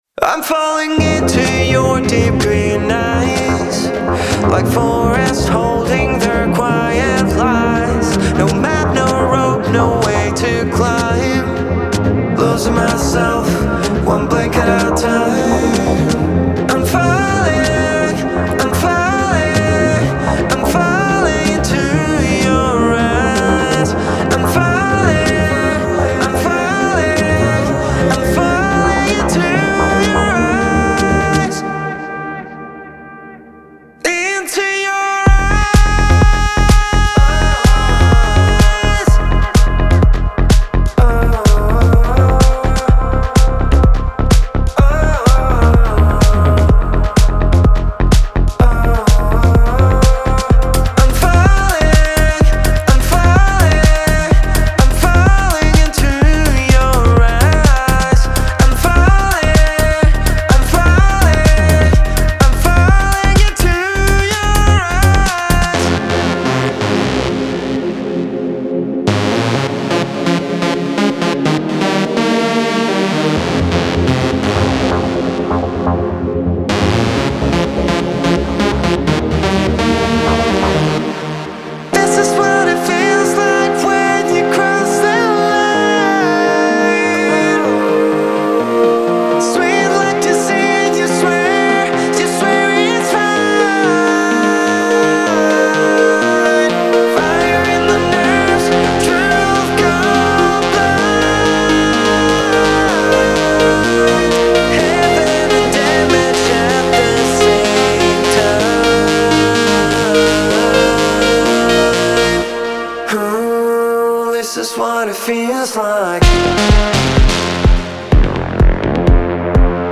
それぞれのボーカルは無限に響き渡り、リスナーをダンスと内省の宇宙的な旅へと誘います。
デモサウンドはコチラ↓
Genre:Melodic Techno
126 BPM
114 Wav Loops (Basses, Synths, Drums, Fills, Vocals, Fx)